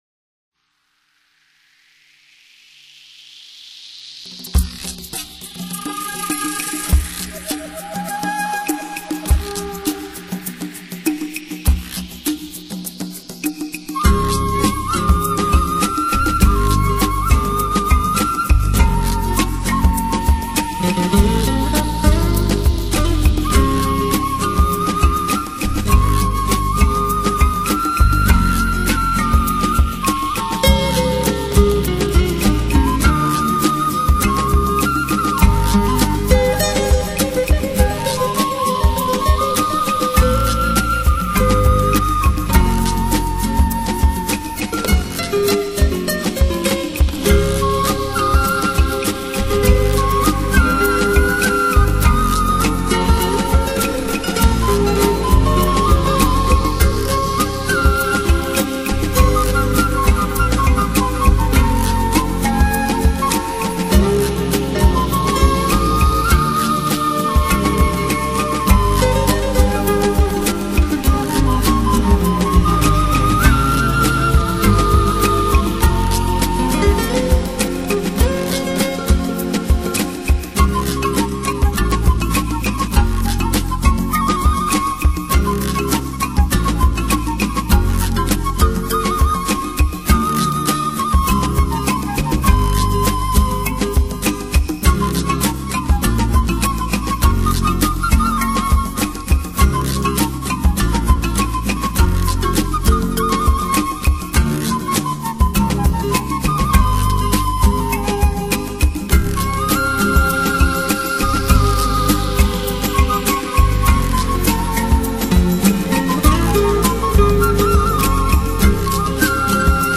【纯音】《十字街头 The Cross of Street》